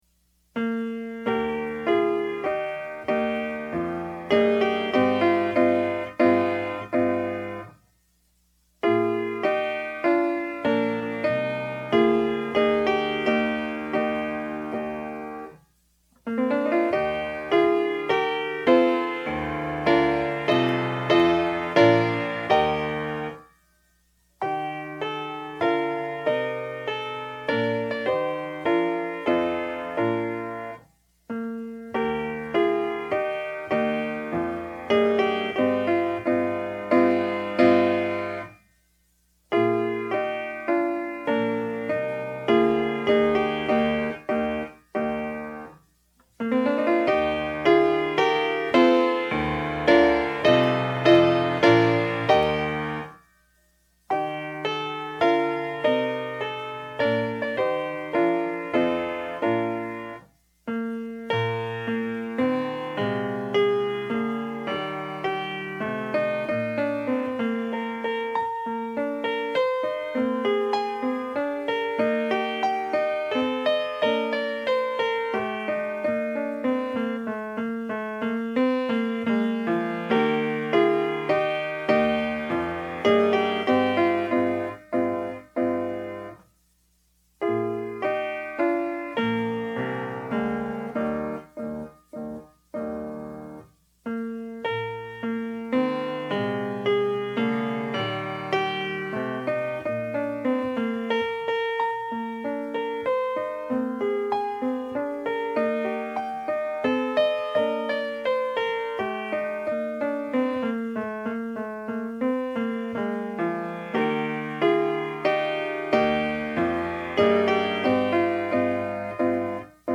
DIGITAL SHEET MUSIC - PIANO SOLO